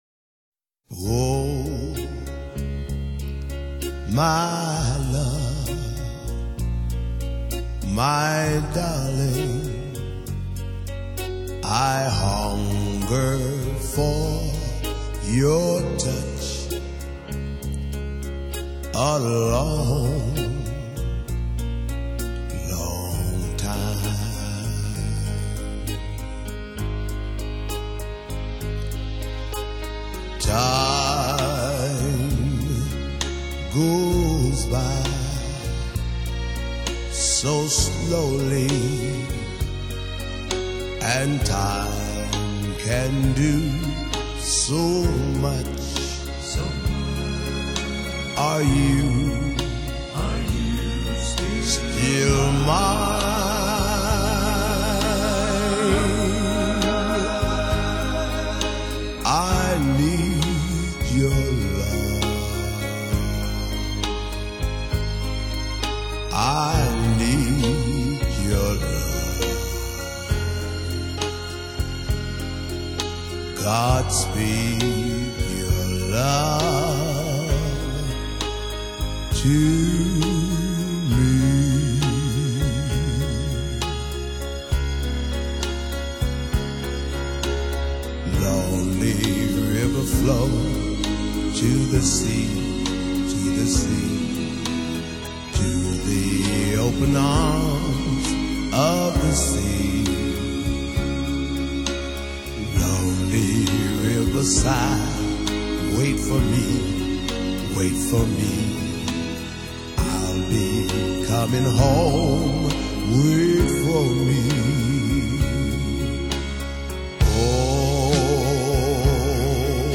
有浪漫温情的，有粗狂豪放的，有节奏流 畅的。